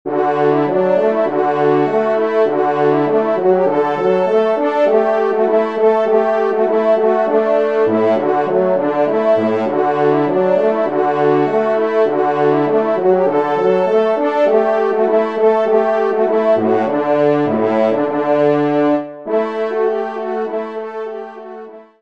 Genre : Divertissement pour Trompes ou Cors
Pupitre 3° Cor